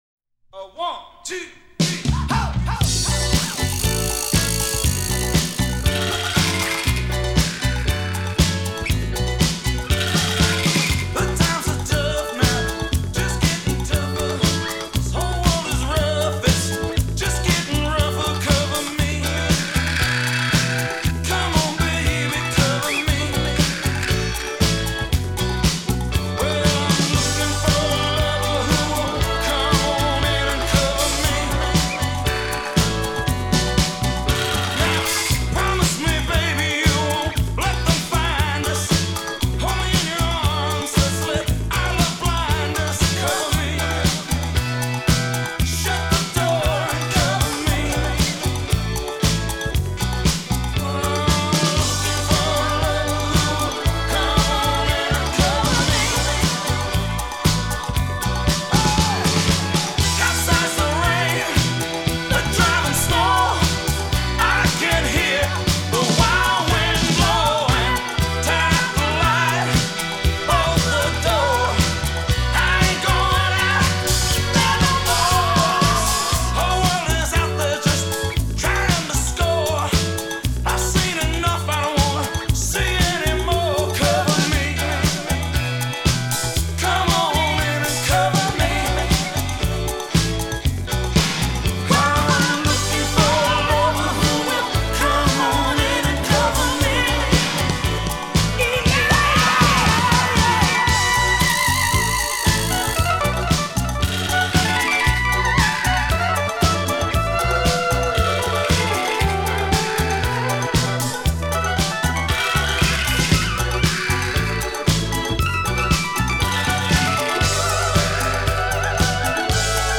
From the 12″ single